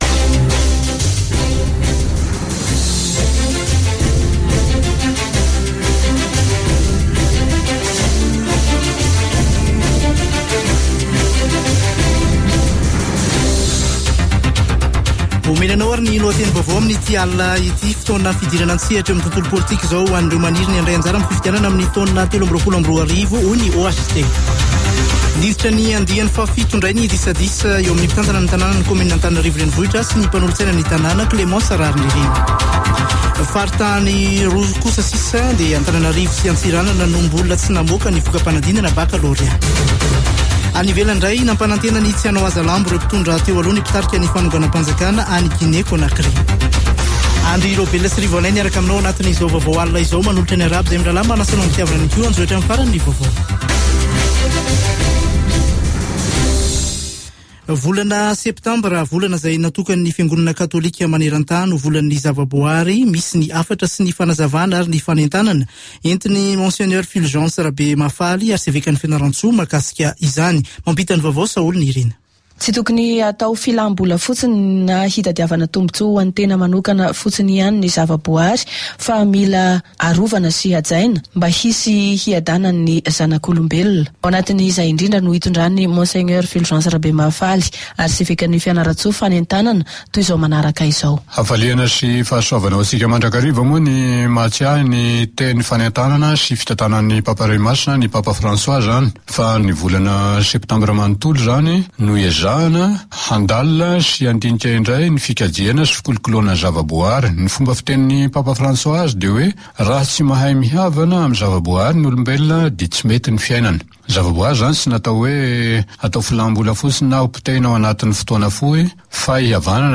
[Vaovao hariva] Alatsinainy 6 septambra 2021